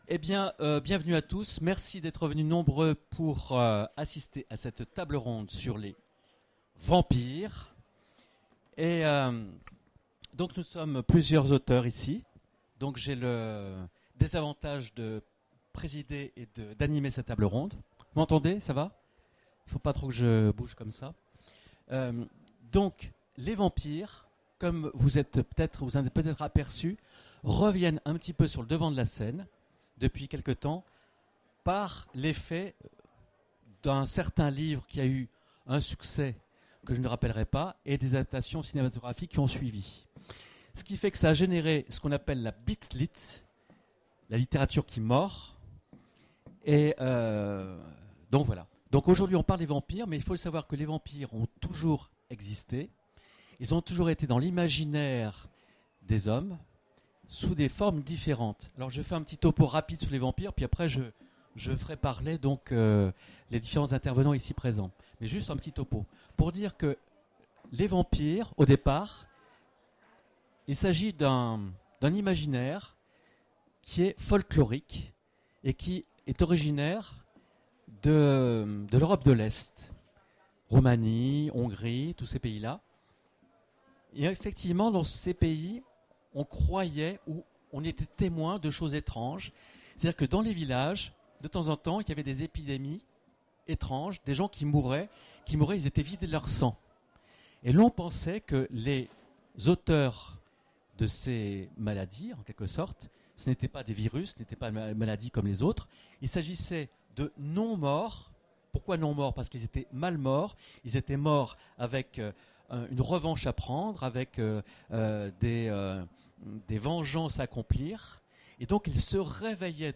Voici l'enregistrement de la conférence sur Les Vampires aux Futuriales 2010